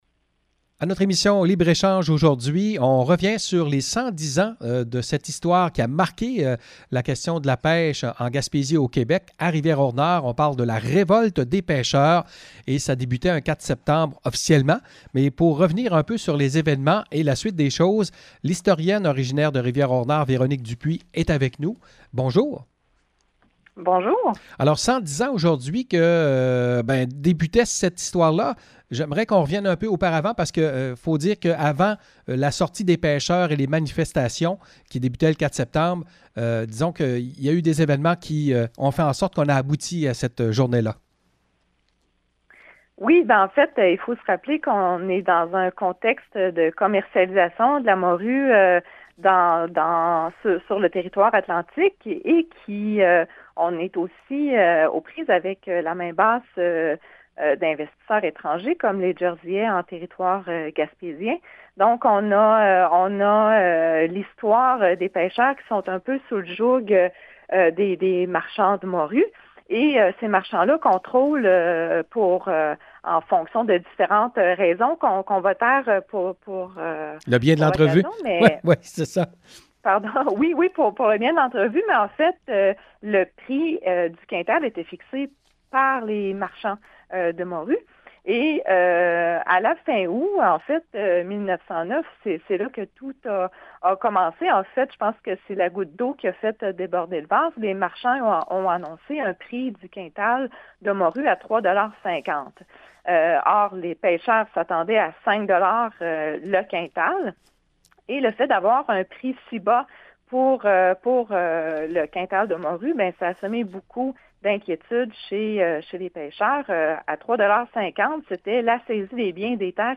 Entrevue avec l’historienne